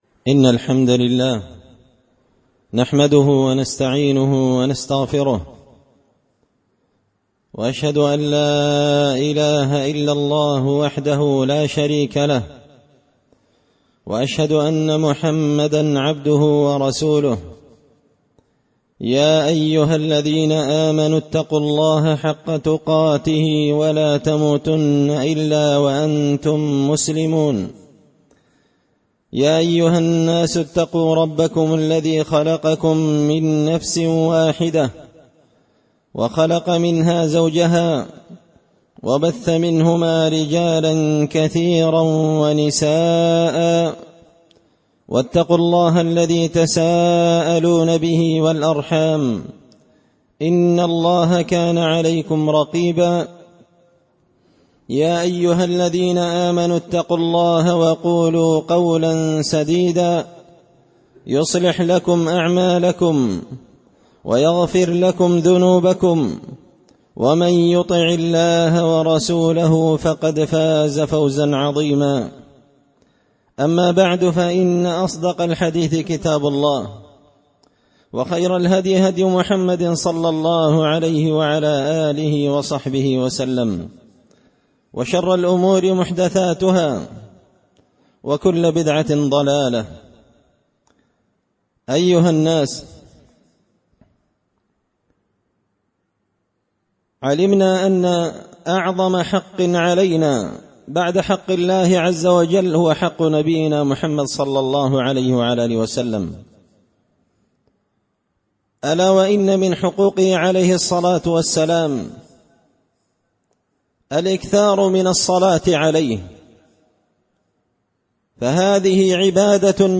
خطبة جمعة بعنوان – حق الرسول صلى الله عليه وسلم الجزء الثالث
دار الحديث بمسجد الفرقان ـ قشن ـ المهرة ـ اليمن